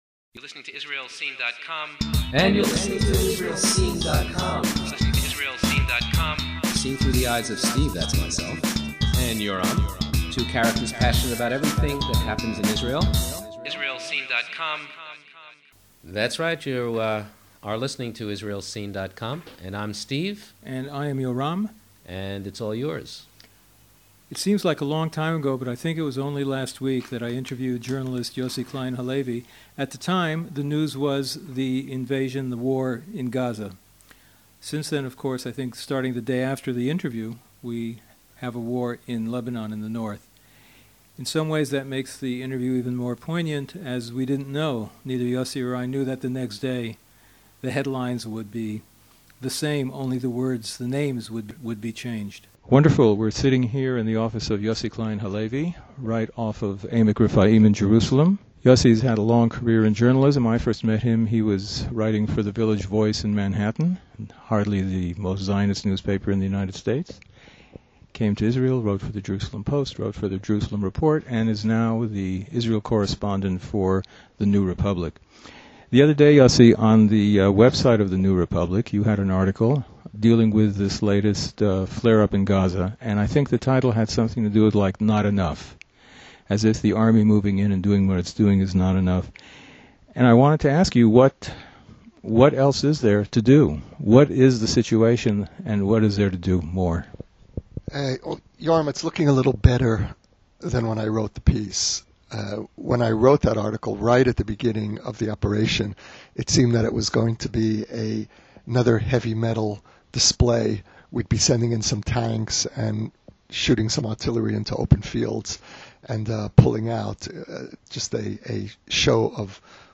Yossi Klein Halevy Interview.